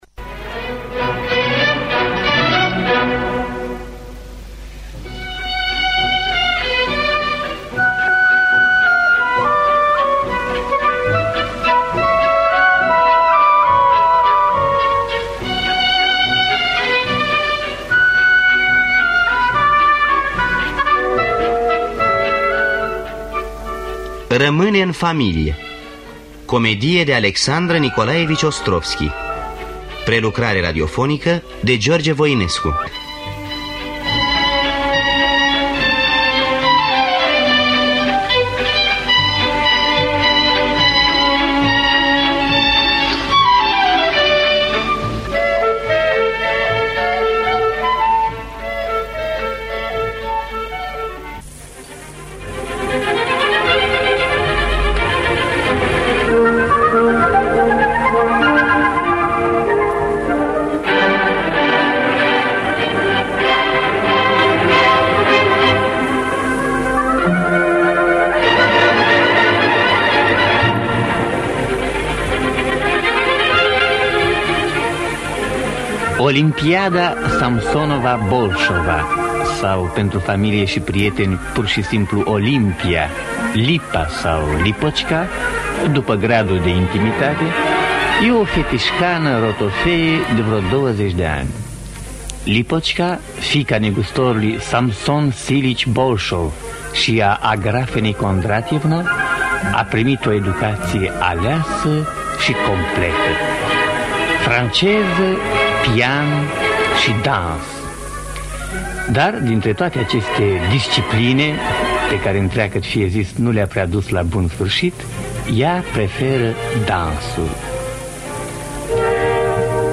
Adaptarea radiofonică
Înregistrare din anul 1953 (22 noiembrie).…